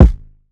Glass Kick.wav